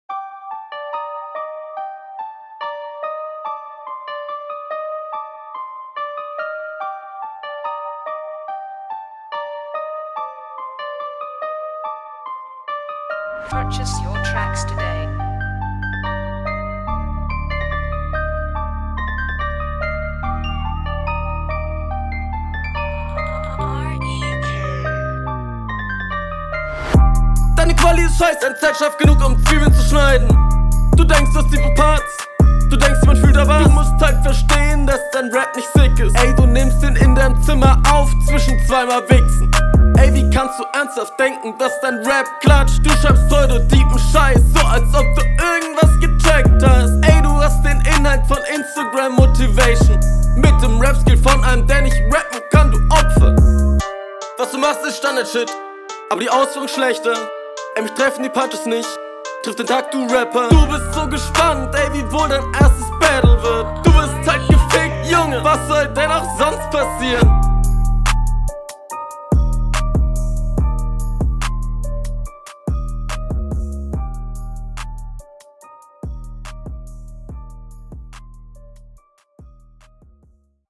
Yo n paar cuts sind zu hören und nicht alle lines sind super clean, aber …